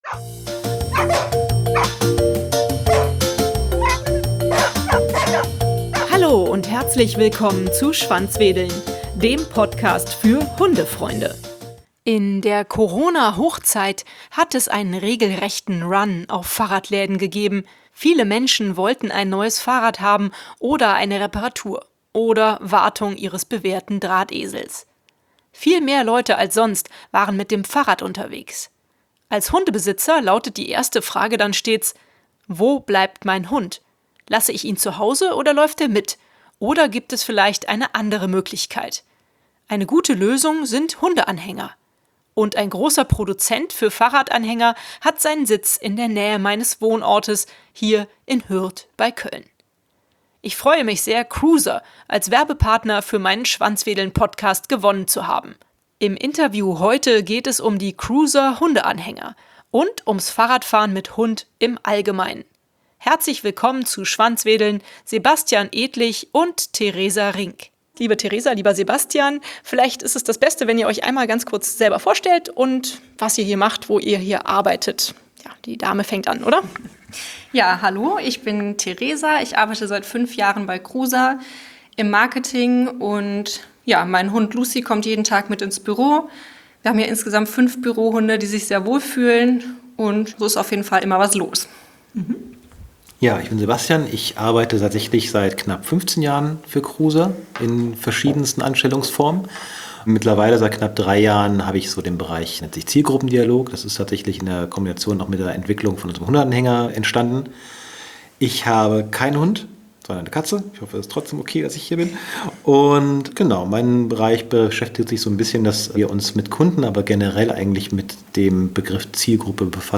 Im Interview heute geht es um die CROOZER Hundeanhänger und ums Fahrradfahren mit Hund im Allgemeinen.